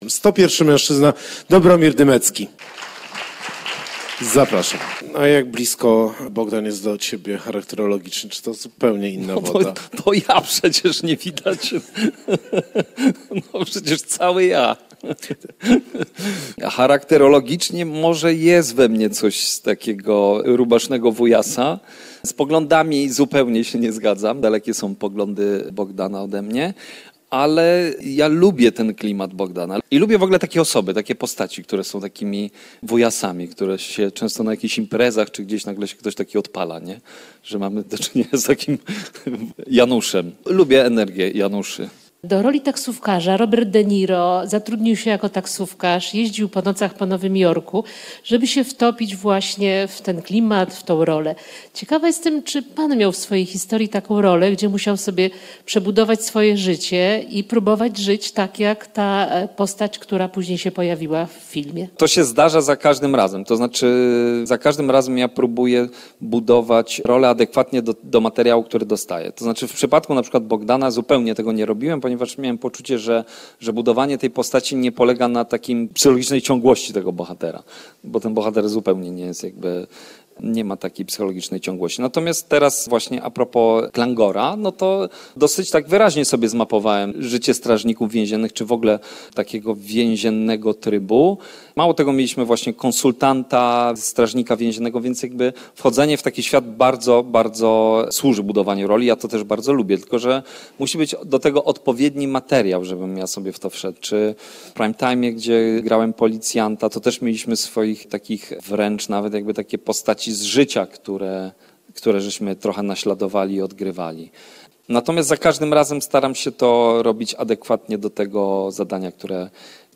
Gościem cyklu „Bitwa o kulturę” był Dobromir Dymecki – aktor teatralny, filmowy i telewizyjny.